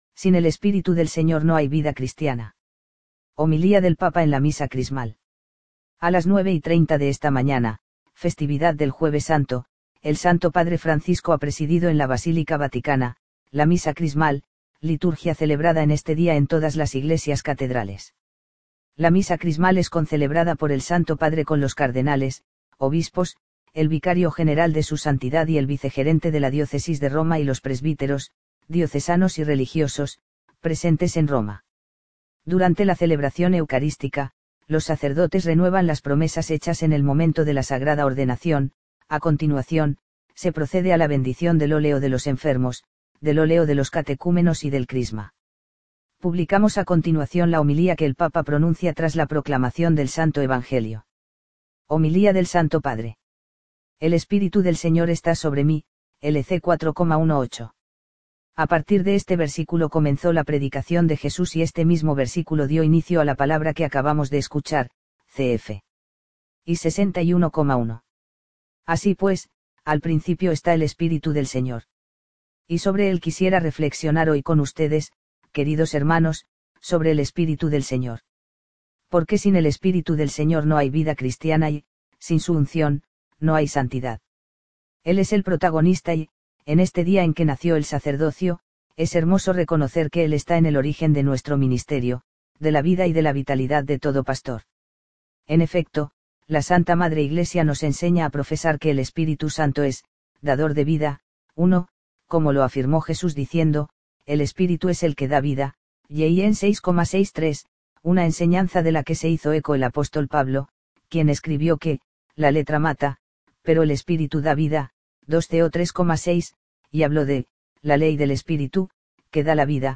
Homilía del Papa en la Misa Crismal
A las 9.30 de esta mañana, festividad del Jueves Santo, el Santo Padre Francisco ha presidido en la Basílica Vaticana, la Misa Crismal, Liturgia celebrada en este día en todas las iglesias catedrales.